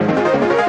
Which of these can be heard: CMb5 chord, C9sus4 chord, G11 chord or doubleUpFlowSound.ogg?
doubleUpFlowSound.ogg